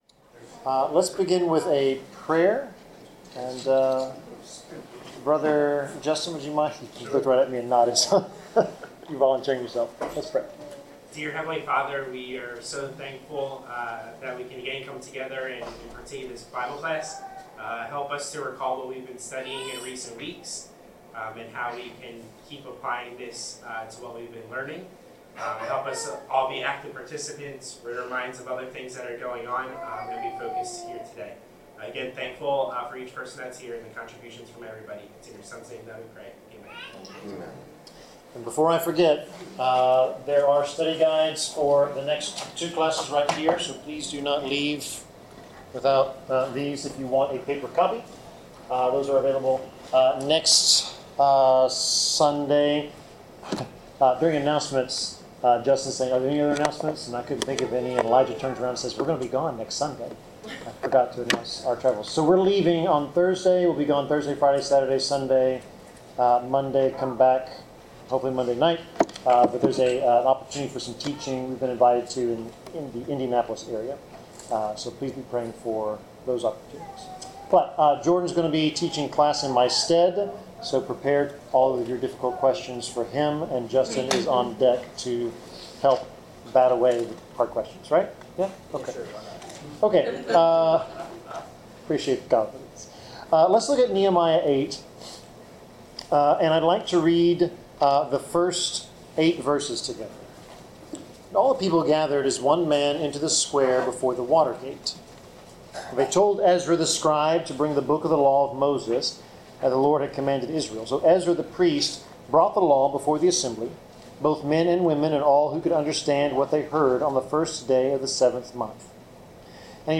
Bible class: Nehemiah 8-10
Service Type: Bible Class Topics: Attentiveness , Confession , Distractions , Forgiveness , Joy of the LORD , Public Scripture Reading , Remembrance , Repentance , The Law « The Book of Signs